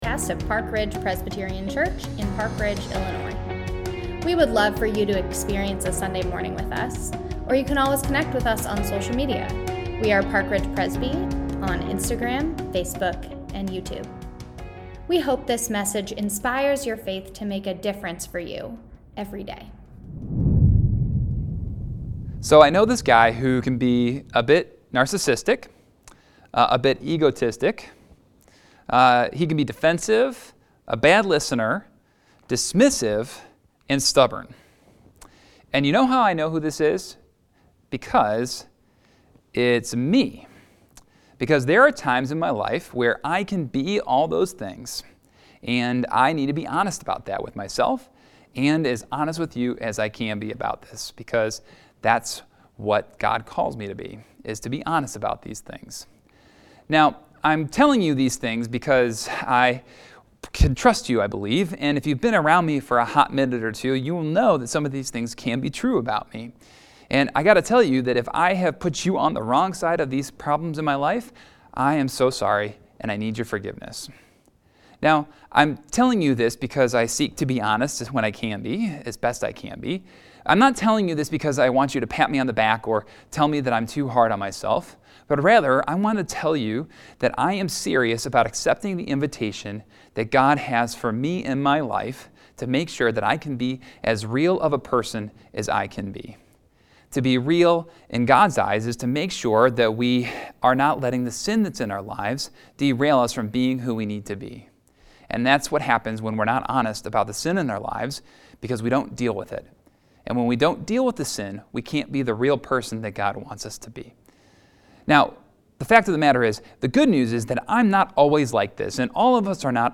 Get Real Week 1 | Online Worship 10am | March 6, 2022 | Lent 2022